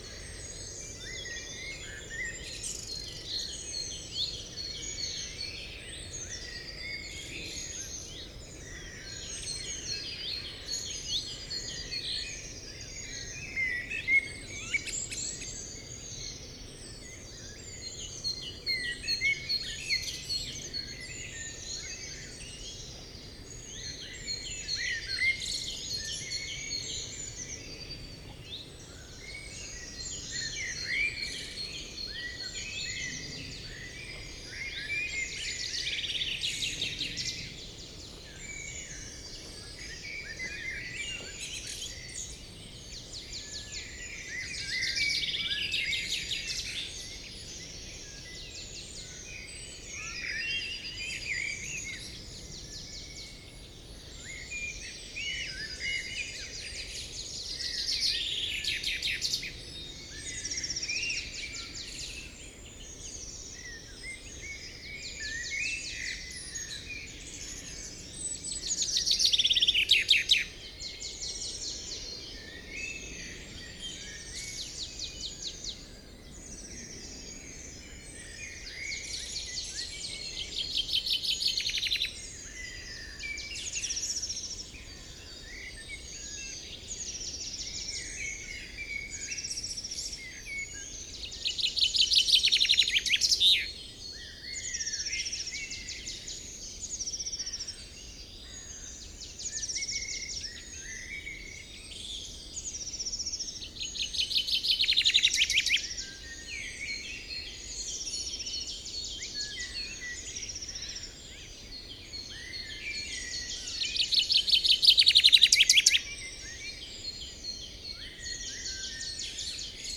morning_in_the_forest_2007_04_15
Category 🌿 Nature
ambient bird birdsong chaffinch dusk environmental-sounds-research field-recording forest sound effect free sound royalty free Nature